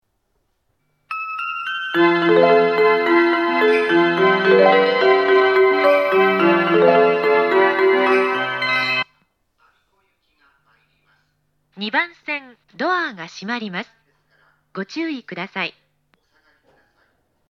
当駅の発車メロディーは音質が大変良いです。
発車メロディー
余韻切りです。